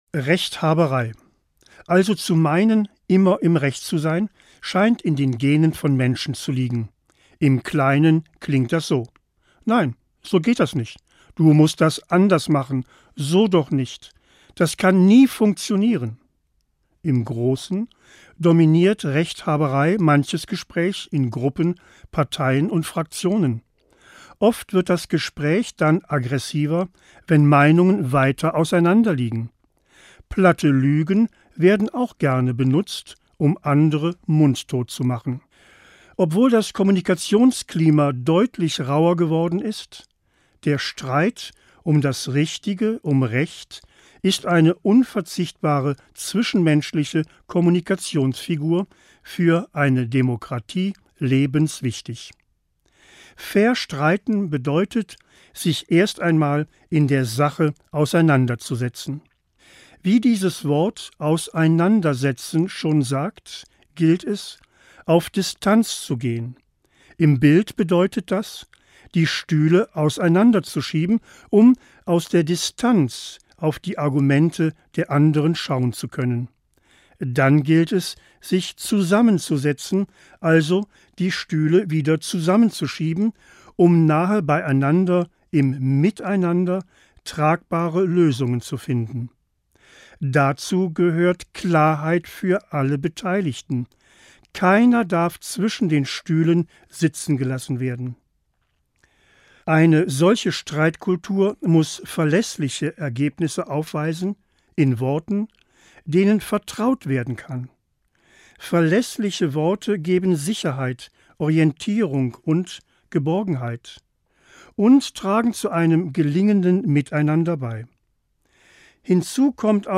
Morgenandacht 11.10.